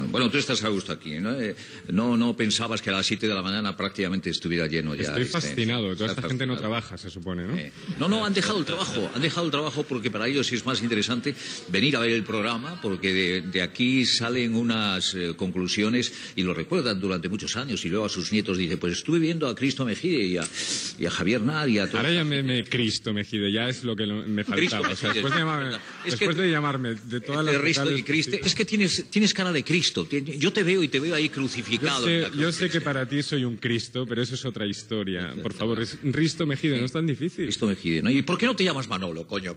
Conversa amb el col·laborador Risto Mejide.
Info-entreteniment